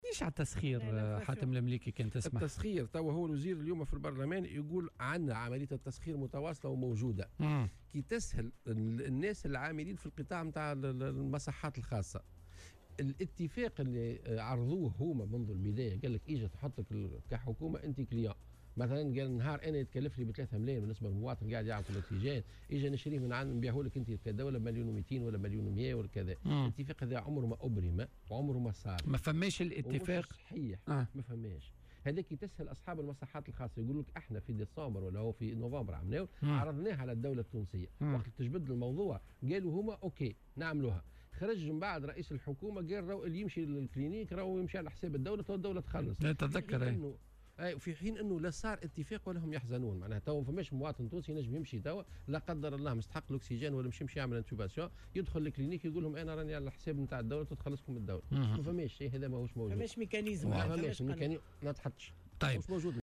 وأفاد ضيف "بوليتيكا" على "الجوهرة أف أم" أن المصحات الخاصّة كانت وافقت إبّان الموجة الأولى من الجائحة على آلية التسخير التي قد تلجأ اليها الدولة على أن يكون ذلك بسعر تفاضلي تسدده الحكومة للمصحات الخاصة مقابل ايوائها مرضى كورونا، مؤكدا أنه لم يتم فعليا ابرام هذا الاتفاق.